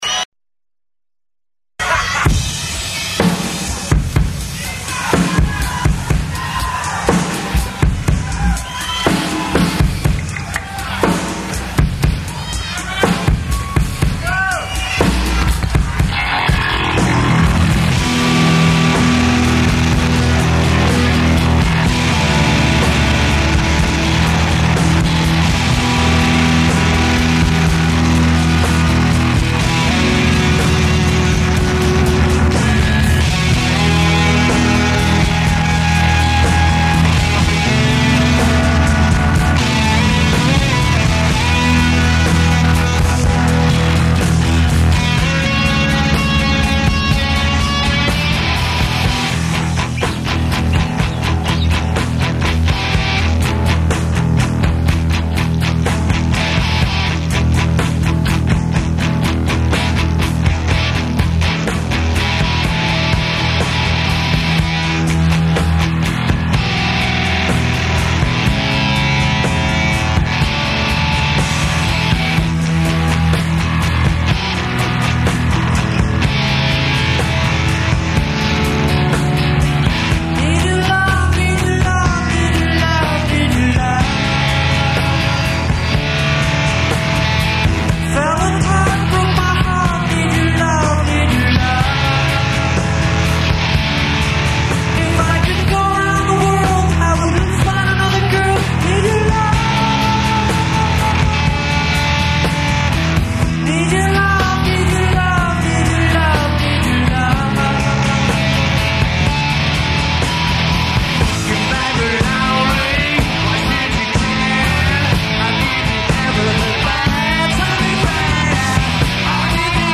hypnotic
in concert